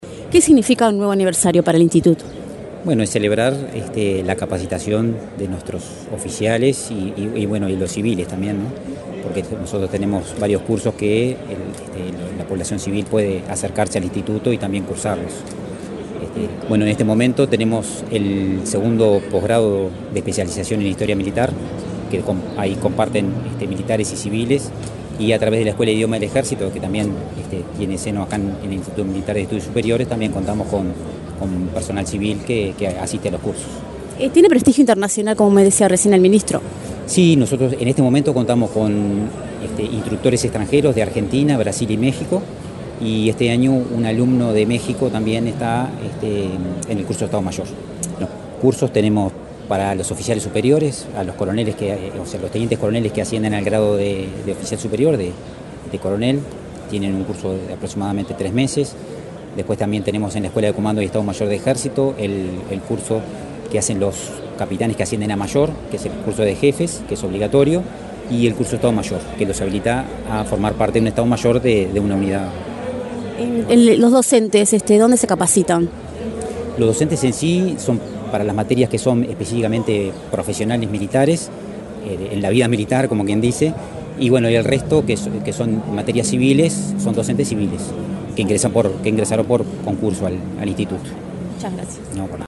Entrevista al director general del IMES, Alejandro Córdoba